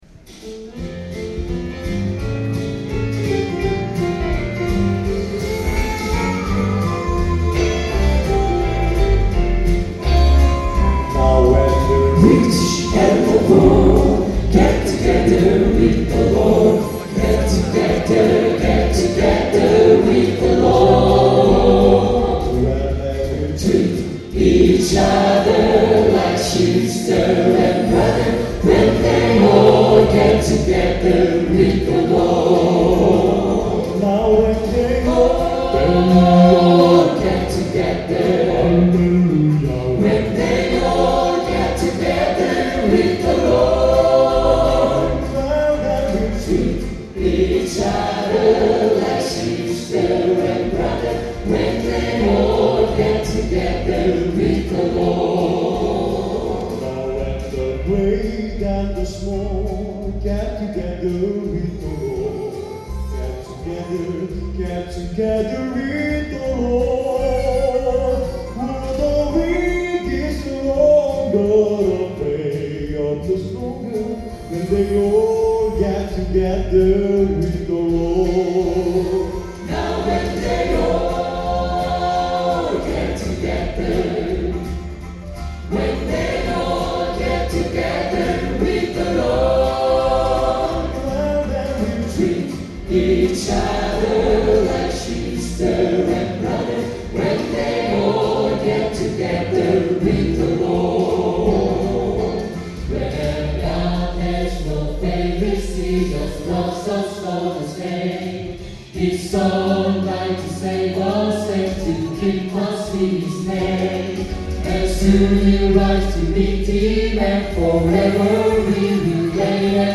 Last night, I was obliged to attend Rhythm of Life, a charity concert in support of the Adventist Home for the Elders, Adventist Nursing and Rehabilitation Centre and Adventist Community Services.
I took the opportunity to test out the in-ear binaurals I got from The Sound Professionals and borrowed an old MiniDisc player.
It being the first time I’ve recorded a live show, I missed out the beginning of the first song.
These songs are best heard on headphones to hear the direction of chatter, laughter and so on.
Sometimes they sounded like an African children’s choir because of the way they pronounce English. They really widen and flatten out the vowels.
The Golden Angels – When We All Get Together. My father talks on the left.